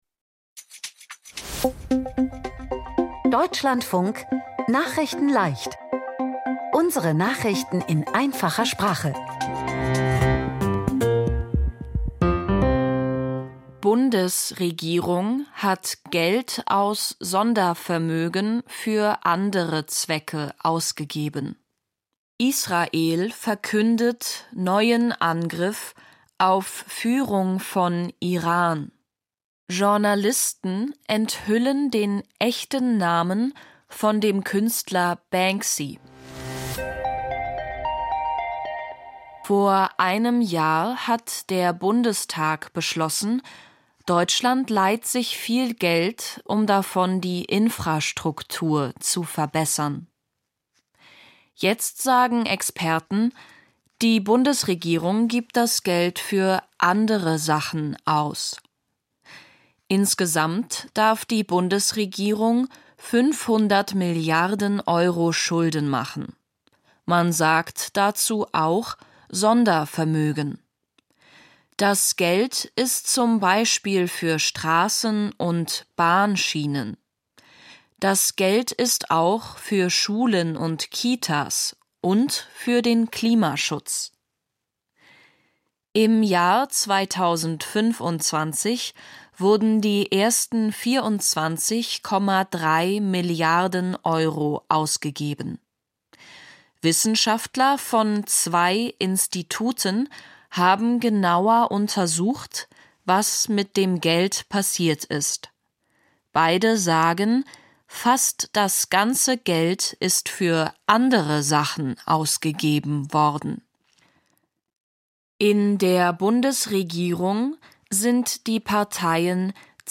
Nachrichten in Einfacher Sprache vom 17.03.2026